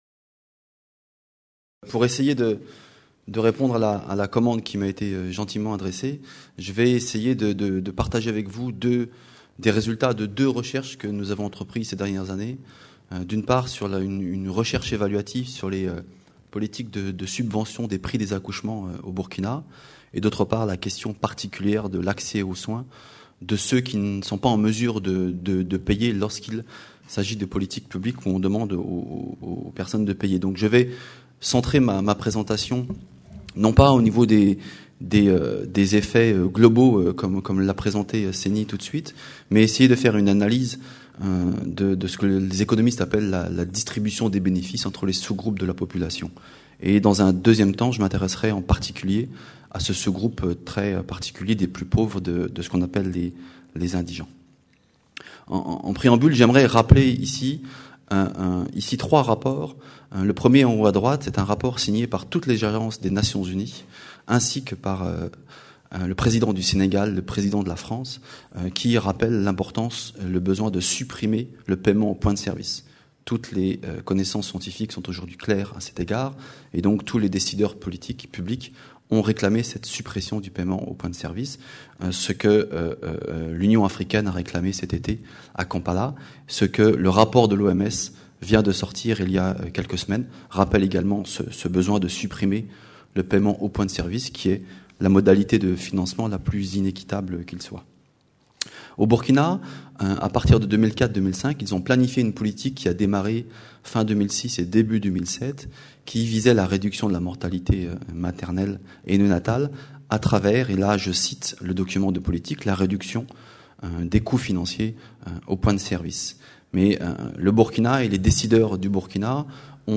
Titre : Indigence et équité d’accès aux services obstétricaux au Burkina Faso. Conférence enregistrée dans le cadre du Colloque International Interdisciplinaire : Droit et Santé en Afrique. Réduction de la mortalité maternelle en Afrique Sub-saharienne, mieux comprendre pour mieux agir. 4ème session : accouchement à domicile, choix ou nécessité.